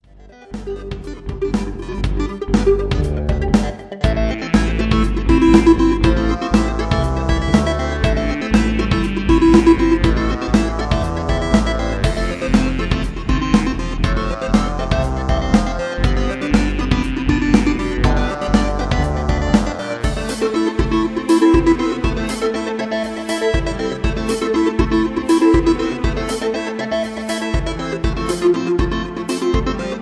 Soft Electro Melodic Ambient loop
Ambiental_Loop228.mp3